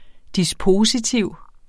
dispositiv adjektiv Bøjning -t, -e Udtale [ disˈpoːsiˌtiwˀ ] Oprindelse fra middelalderlatin dispositivus af latin dispono 'opstille, ordne' Betydninger 1.